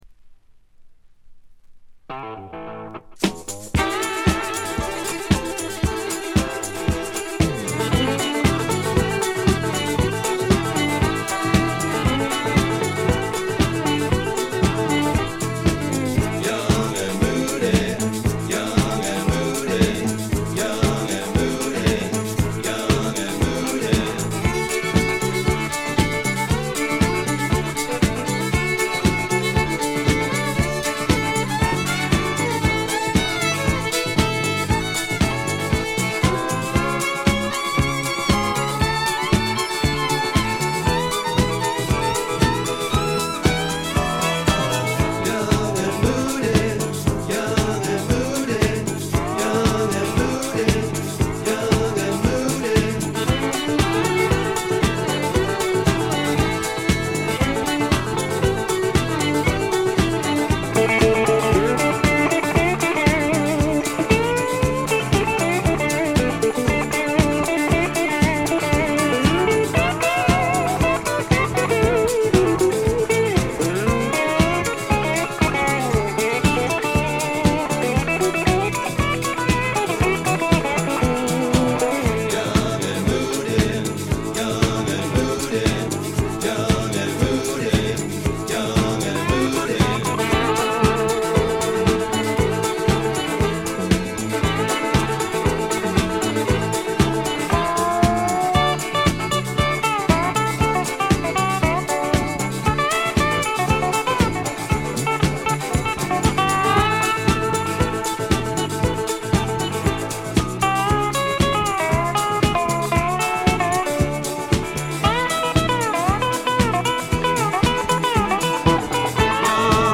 ほとんどノイズ感無し。
ずばり英国スワンプの名作です！
多少枯れぎみの哀愁を帯びた声で、ちょっと投げやりな歌い方もサウンドの雰囲気にマッチしています。
試聴曲は現品からの取り込み音源です。